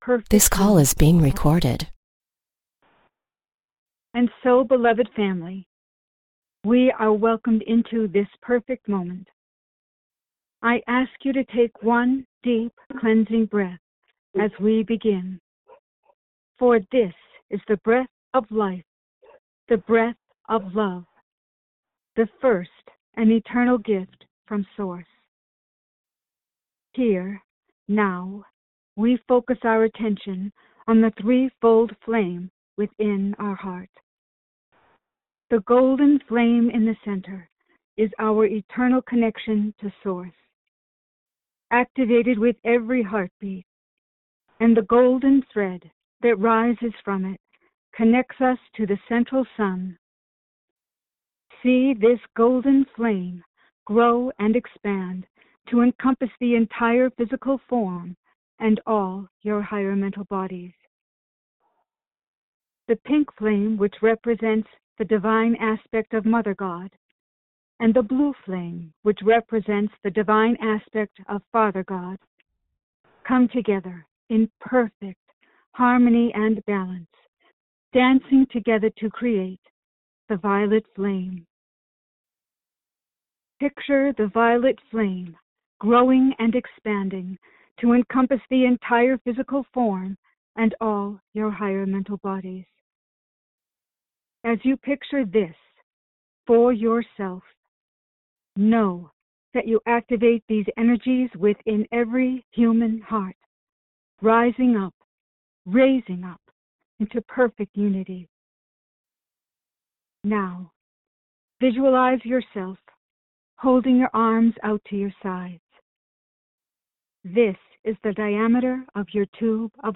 Join Lord Sananda in group meditation.